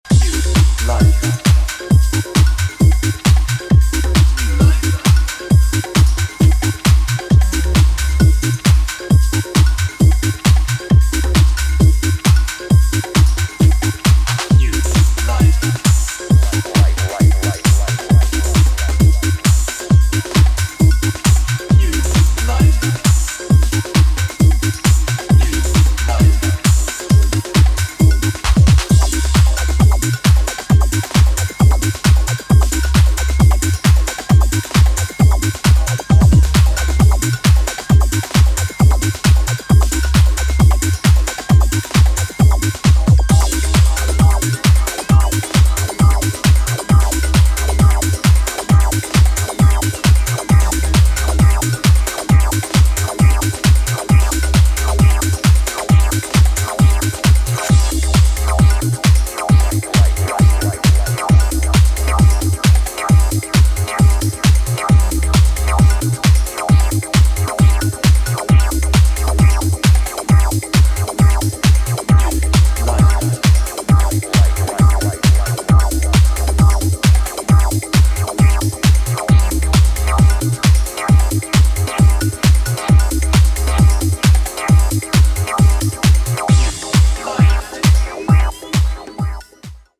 a raw yet simple and minimalist approach to house music